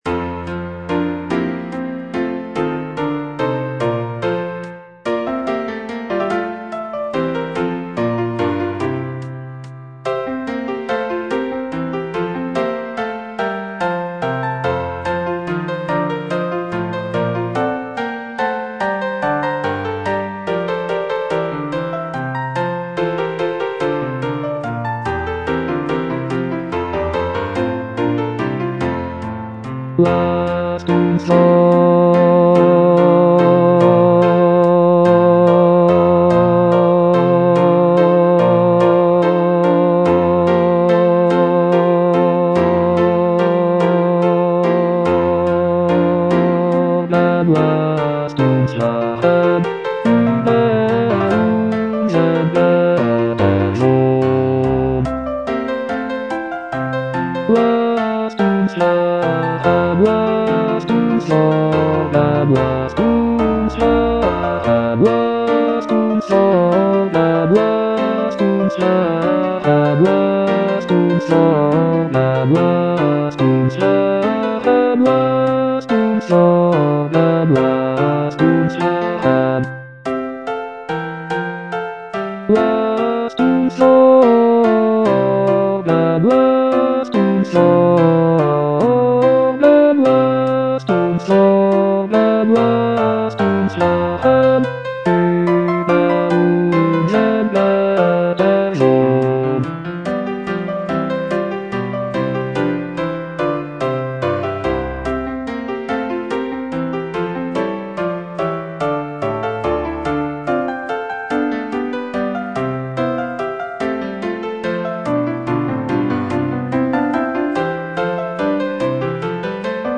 Choralplayer playing Cantata